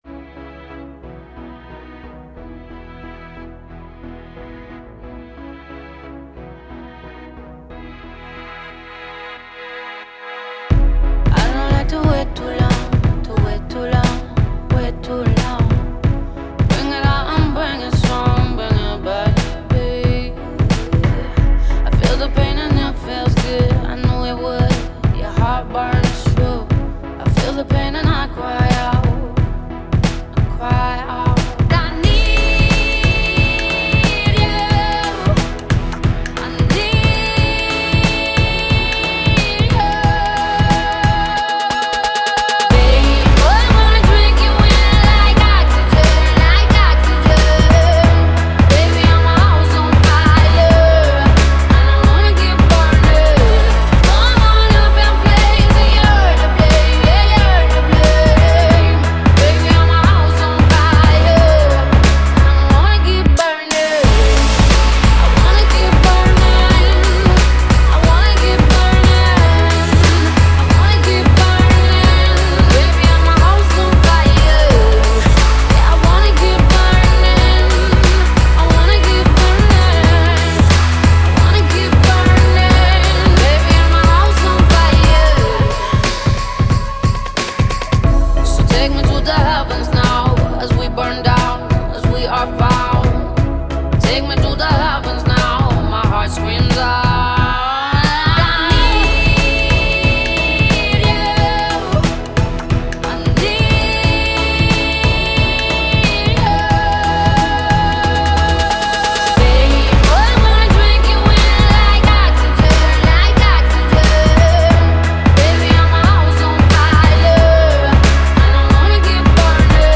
Genre: Alternative, Pop